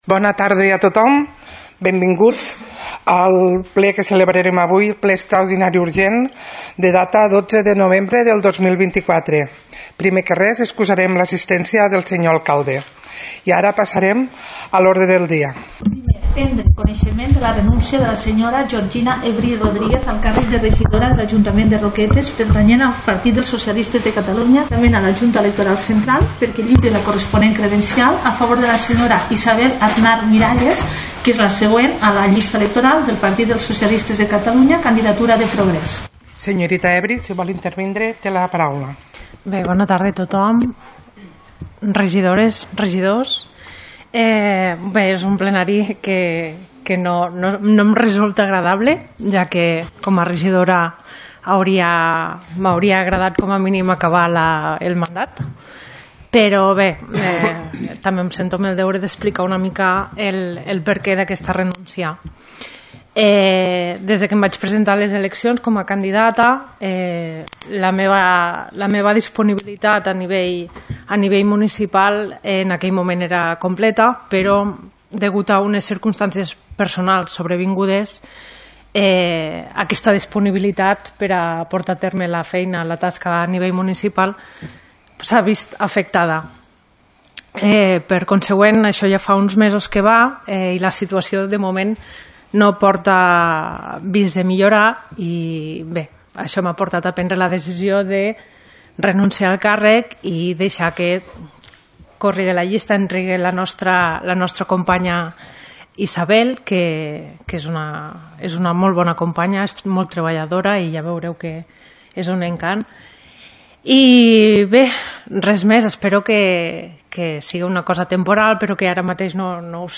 Ple Extraordinari i Urgent de l’Ajuntament de Roquetes del 12 de novembre de 2024 | Antena Caro - Roquetes comunicació
Visiblement emocionada, ha volgut agrair a tots els companys i companyes de plenari el temps compartit, així com als membres de l’agrupació municipal socialista el fet d’haver-li donat “l’oportunitat de conèixer la política municipal”.